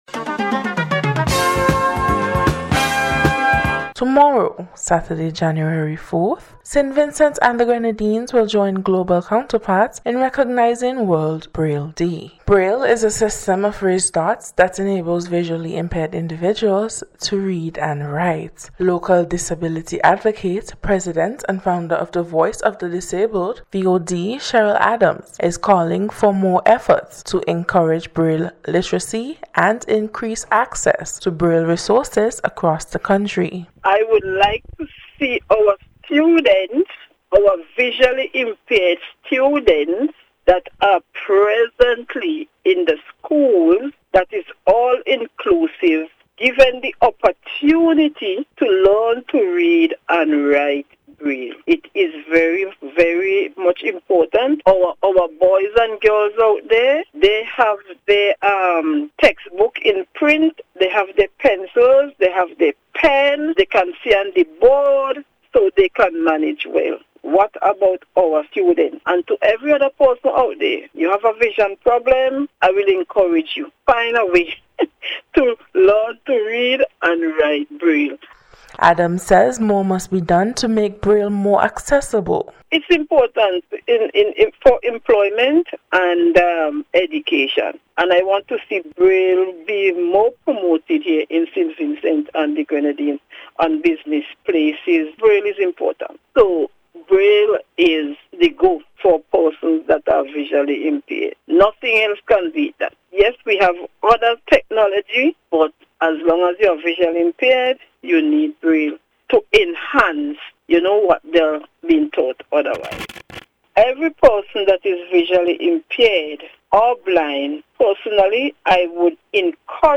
BRAILLE-DAY-REPORT.mp3